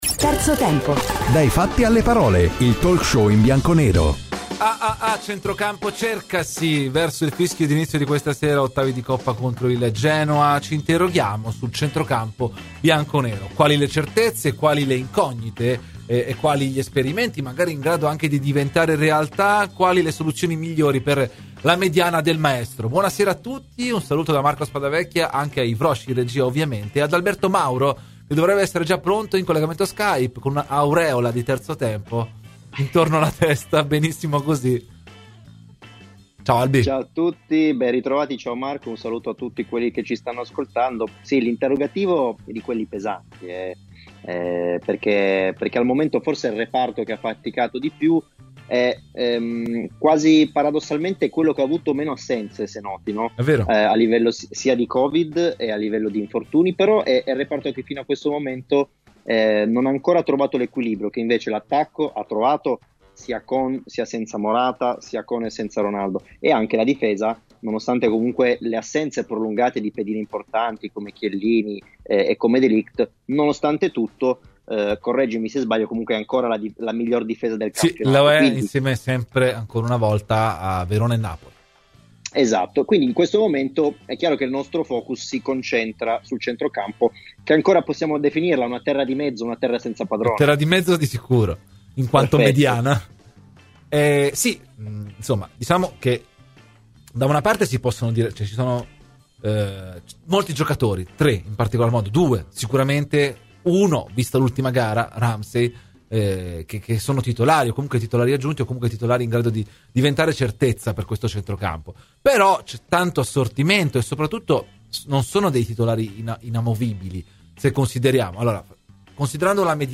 Ai microfoni di Radio Bianconera, nel corso di ‘Terzo Tempo’, è intervenuto il giornalista di Sky Sport Paolo Condò: "Chiesa?